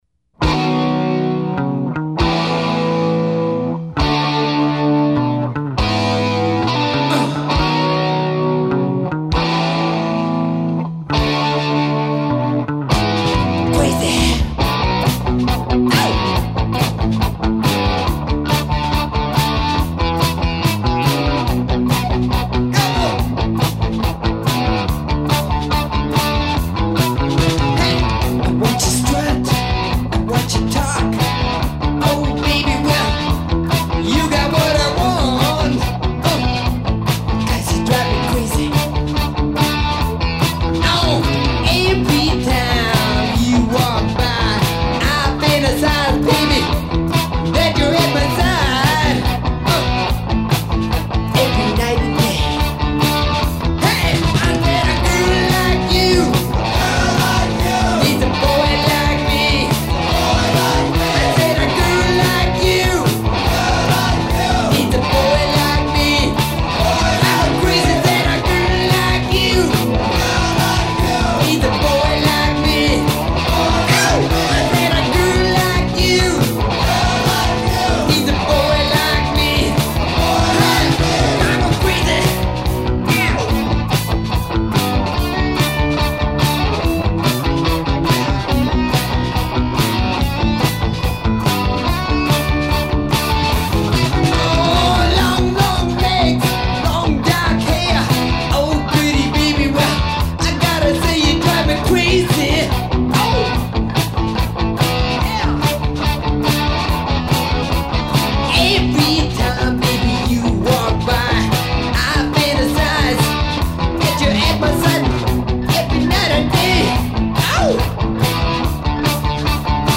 Rhythm guitar
Drummer
Bass